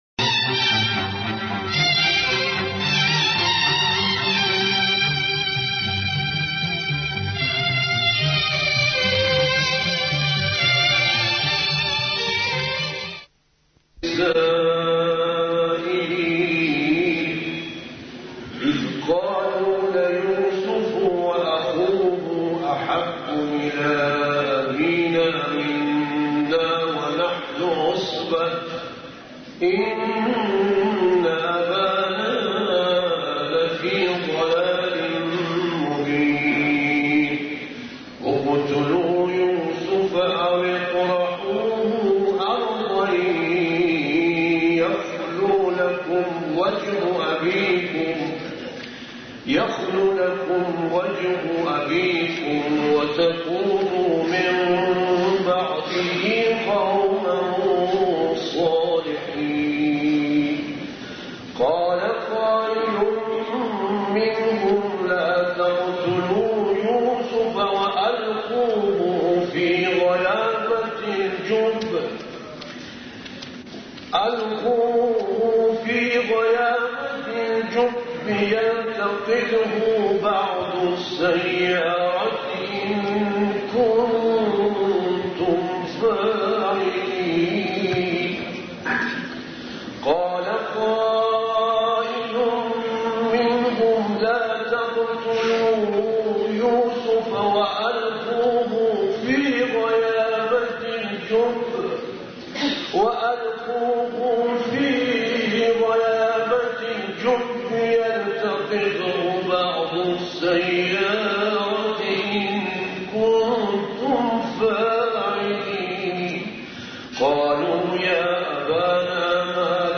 صوت(9) سخنرانی حضرت روحانی شهید حاج صادق احسان‌بخش